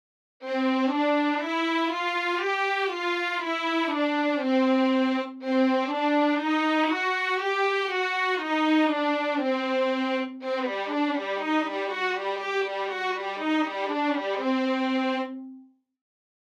This is the Violin I dry ensemble with no other effects.
On the first example, the overlapped note is 30ms early.
I am trying to get the sound of a bowed legato, but to me it still sounds like a bow change between each note.
In the example you posted, I hear a correctly simulated fingered legato, with the following limitations: